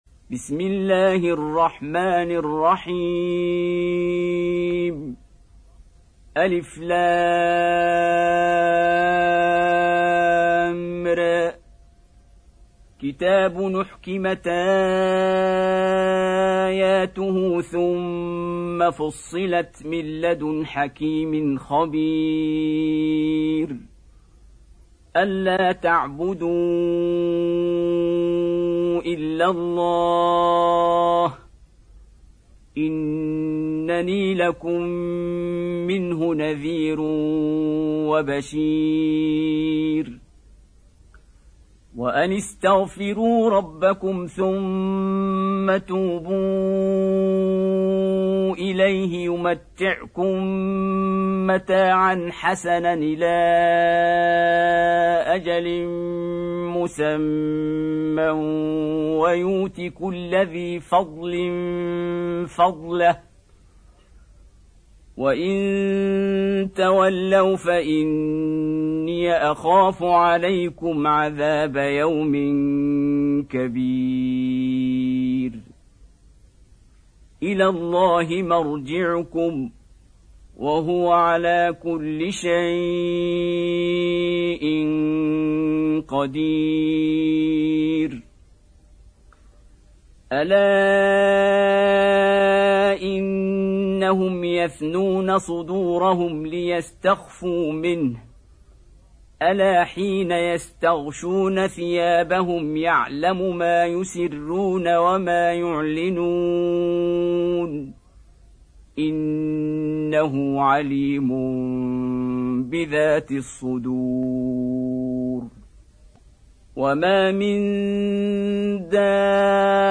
Surah Hud Beautiful Recitation MP3 Download By Qari Abdul Basit in best audio quality.